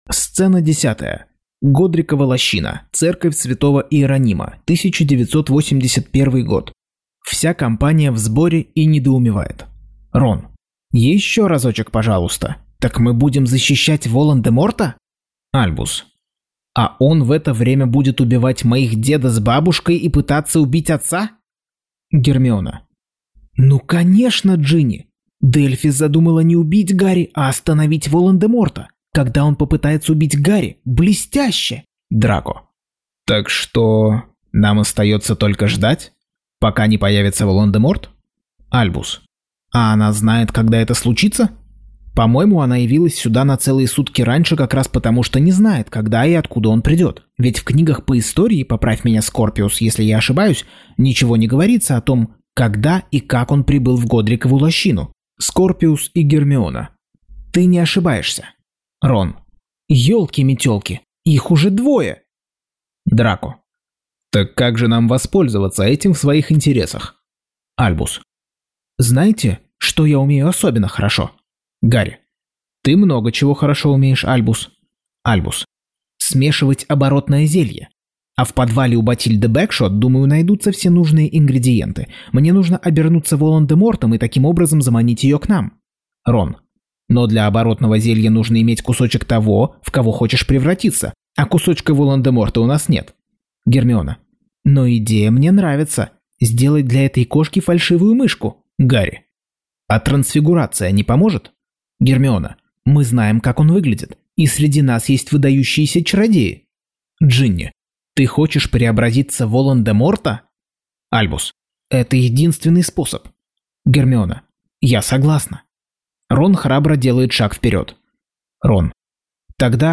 Аудиокнига Гарри Поттер и проклятое дитя. Часть 61.